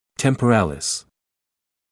[ˌtempəˈreɪlɪs][ˌтэмпэˈрэйлис]височная мышца